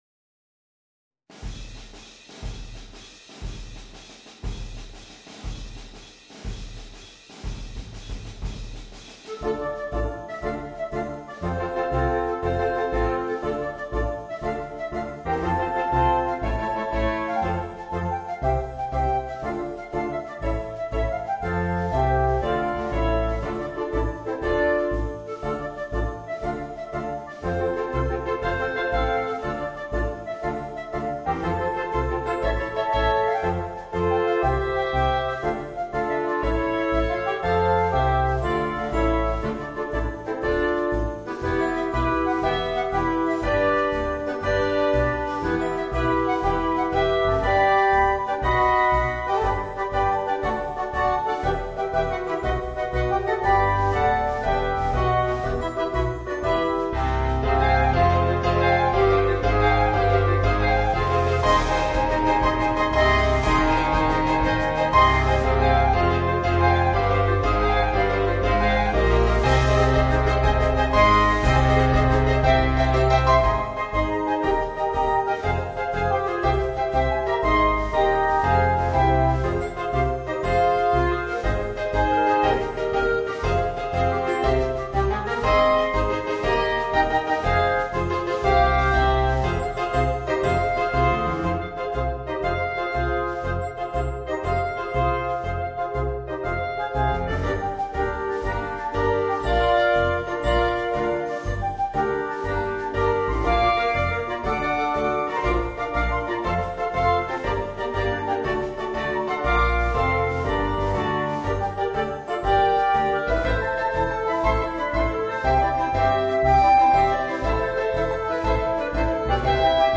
Voicing: 5 Clarinets and Rhythm Section